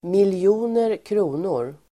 Ladda ner uttalet
mnkr förkortning (i sifferuttryck), million kronor [used with figures]Uttal: [milj'o:ner ²kr'o:nor] Definition: miljoner kronor